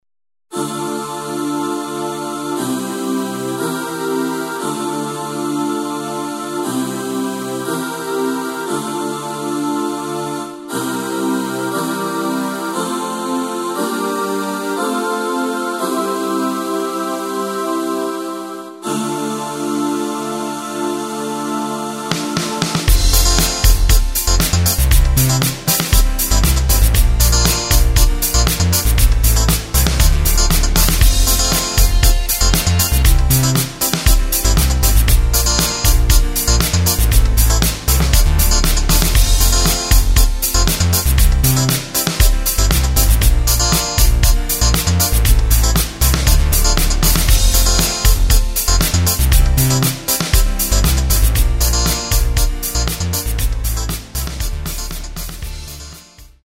Tempo:         118.00
Tonart:            F
Playback mp3 Demo